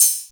OpenHat [Hi Roller].wav